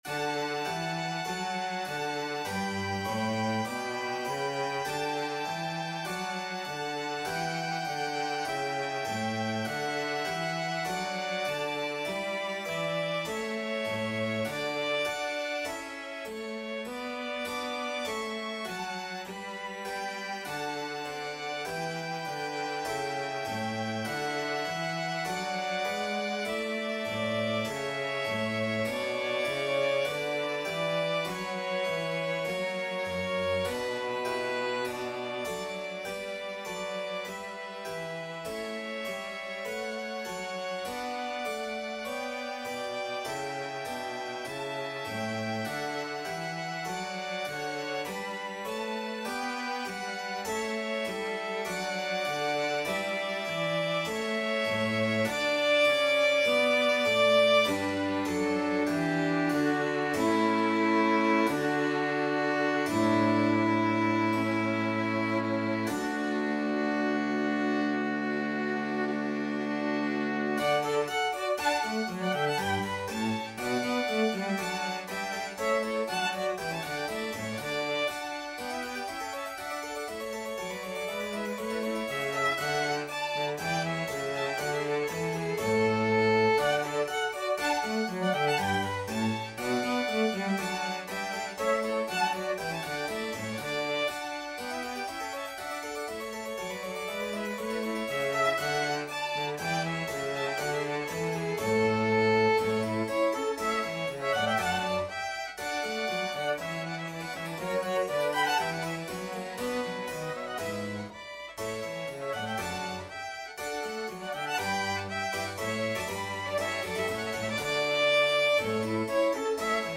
Preludio: Largo
4/4 (View more 4/4 Music)
Classical (View more Classical 2-Violins-Cello Music)